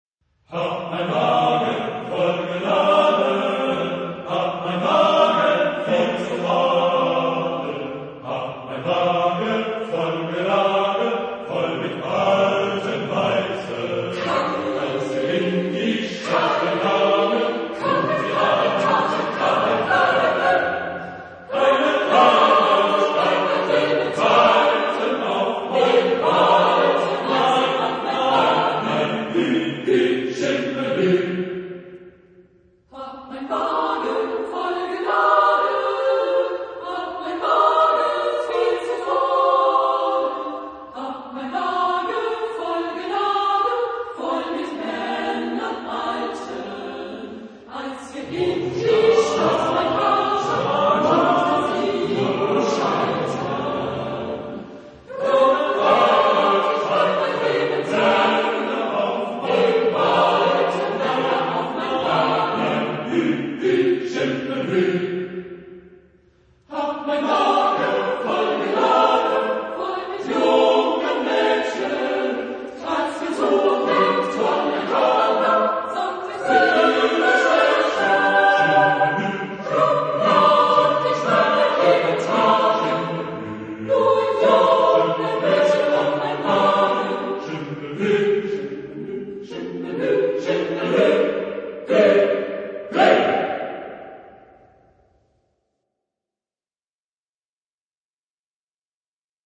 Genre-Style-Form: Folk music ; Partsong ; Secular
Type of Choir: SSATTBB  (7 mixed voices )
Tonality: F major